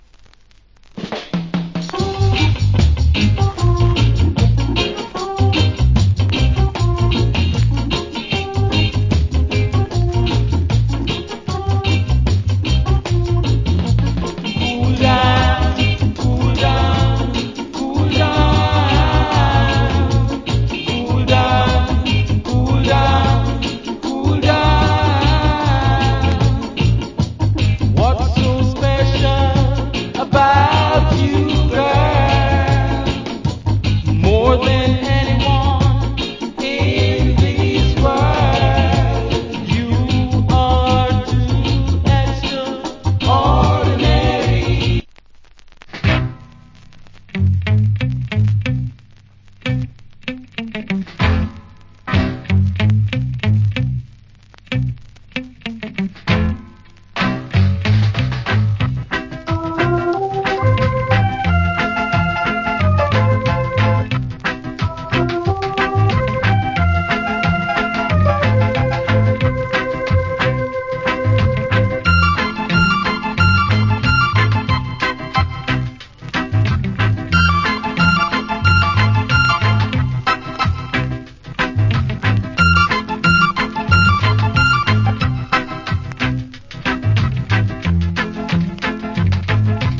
コメント Wicked Early Reggae Vocal.
Nice Early Reggae Inst.